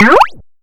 Bounce
2d 3d adventure arcade audio boing bounce cartoon sound effect free sound royalty free Movies & TV